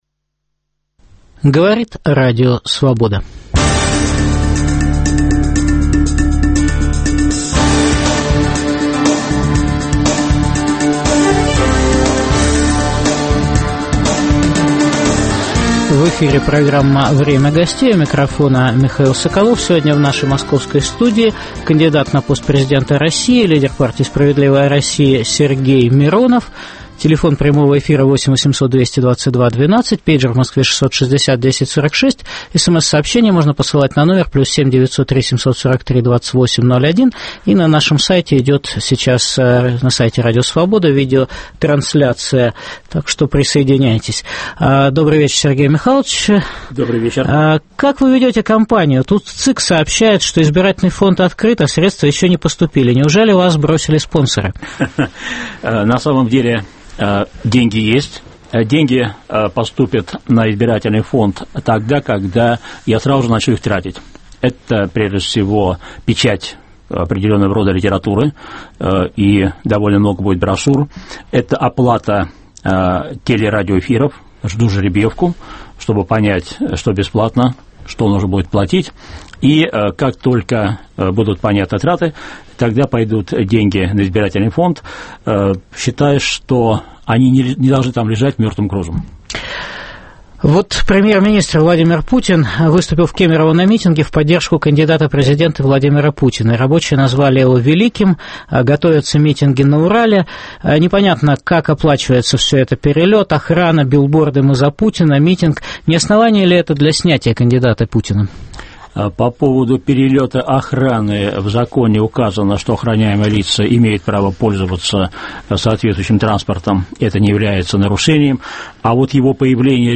Что Сергей Миронов может предложить России как президент "переходного периода"? В программе выступит кандидат на пост президента России, лидер партии "Справедливая Россия" Сергей Миронов.